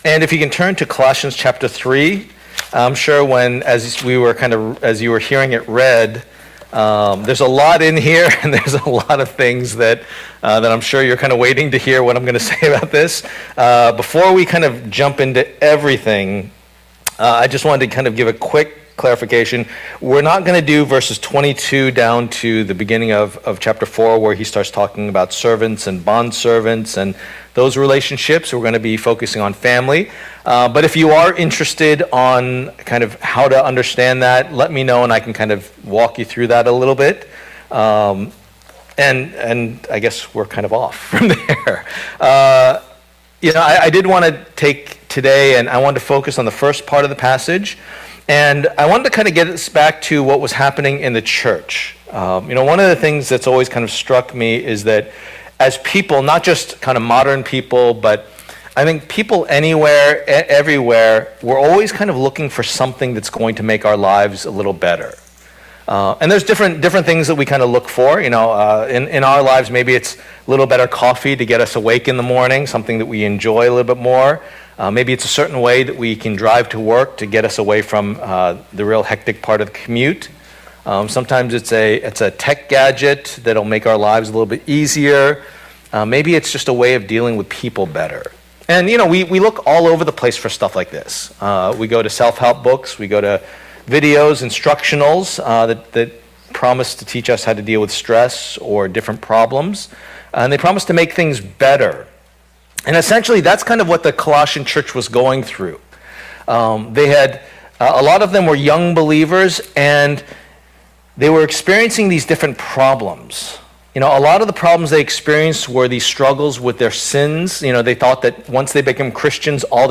Passage: Colossians 3:18-4:1 Service Type: Lord's Day